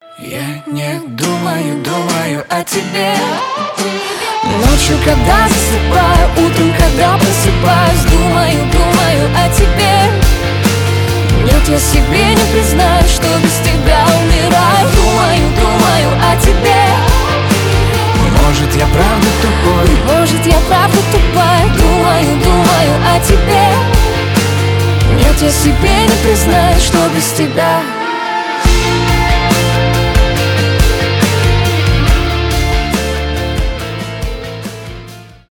рок
pop rock
дуэт